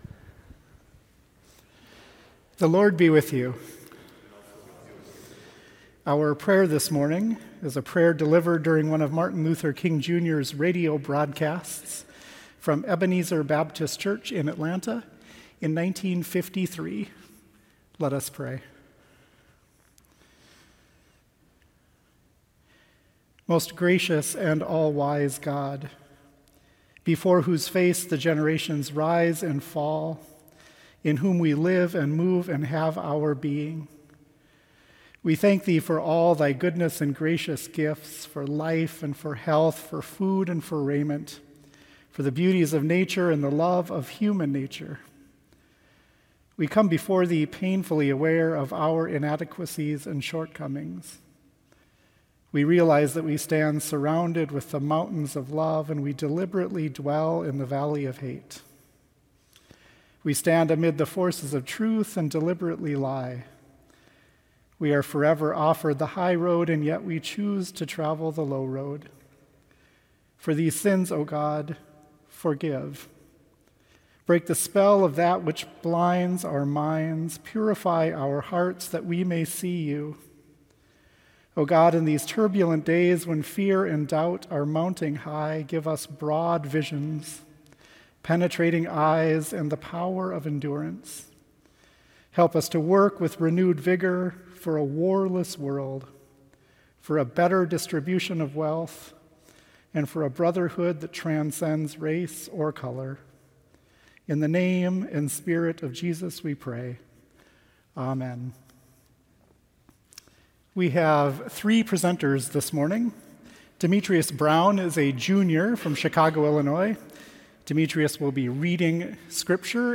St. Olaf College — Chapel Service for Mon, Jan 16, 2017
Boe Memorial Chapel